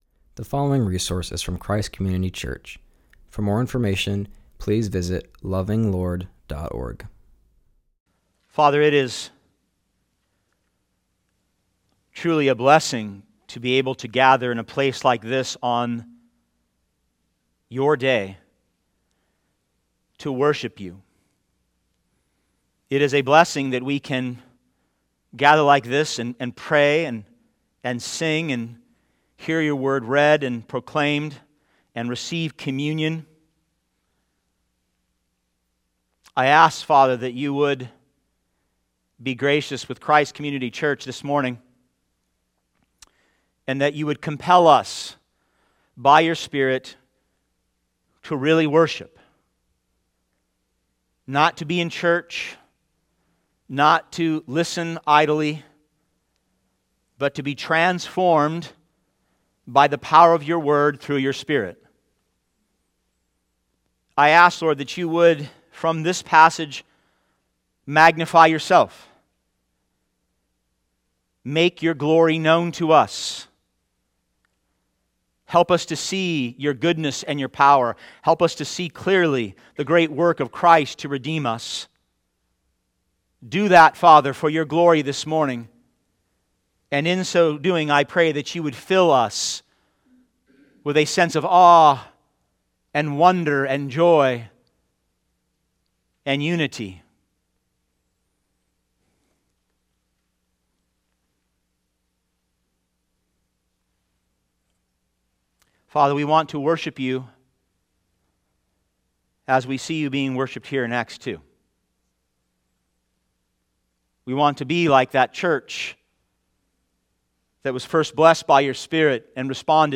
preaching on Acts 2:42-47.